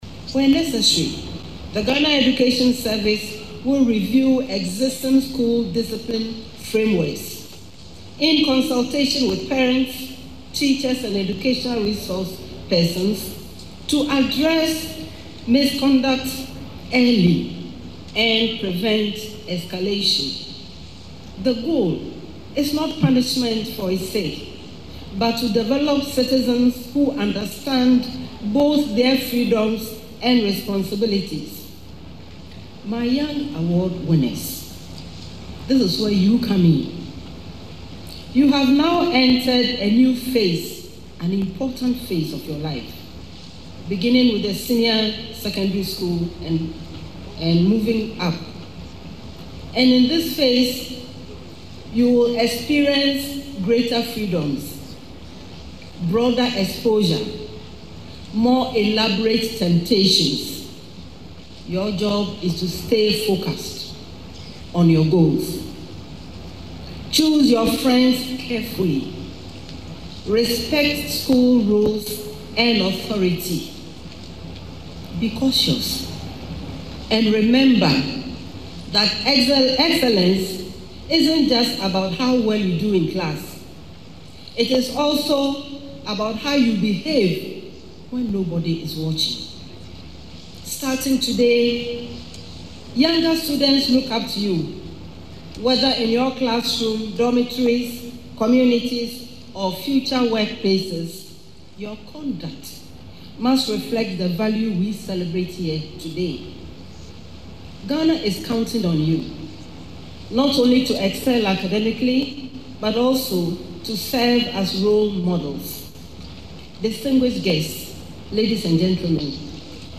Professor Opoku-Agyemang made the announcement when she served as the Distinguished Guest of Honour at the 69th President’s Independence Day Awards Ceremony held at the Accra International Conference Centre.
Addressing the young award recipients, the Vice President congratulated them for their academic excellence and urged them to remain disciplined and focused as they enter what she described as an important phase of their lives.
LISTEN TO THE VICE PRESIDENT IN THE AUDIO BELOW: